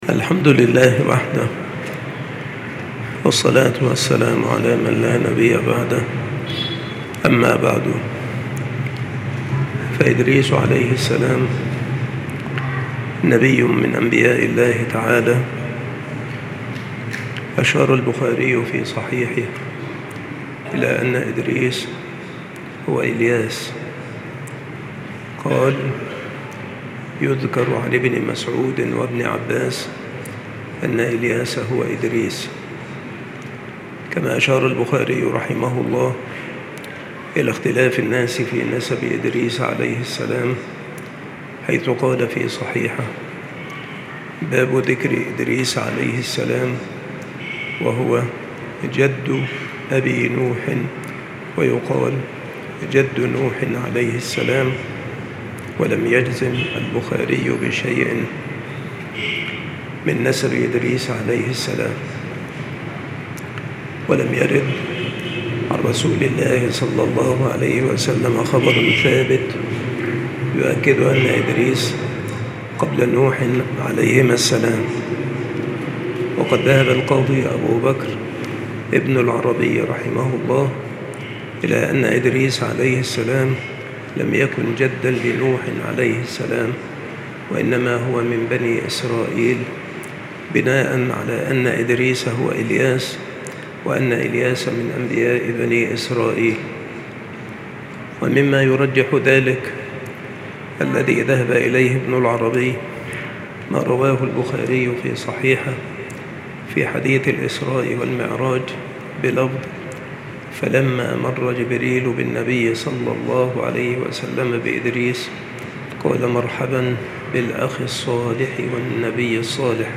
التصنيف قصص الأنبياء
مكان إلقاء هذه المحاضرة بالمسجد الشرقي - سبك الأحد - أشمون - محافظة المنوفية - مصر